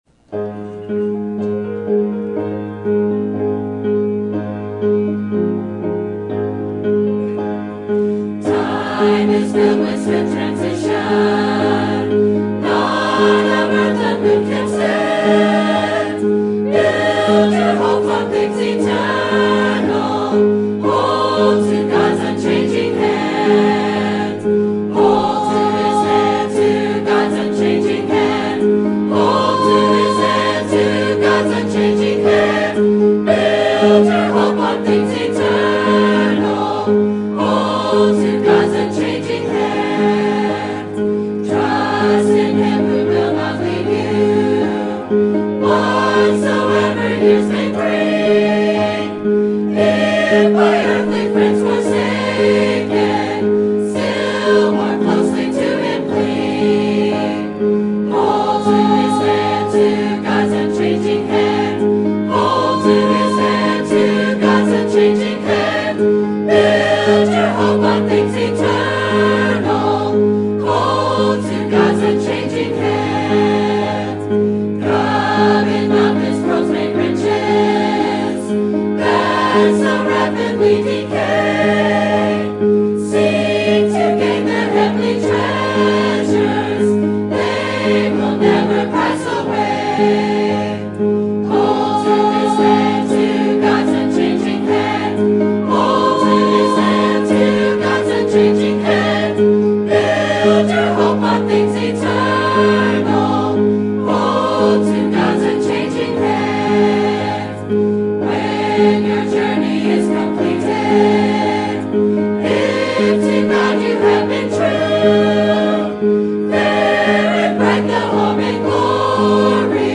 Sermon Topic: Life of Kings and Prophets Sermon Type: Series Sermon Audio: Sermon download: Download (31.51 MB) Sermon Tags: 2 Kings Kings Prophets Shunemite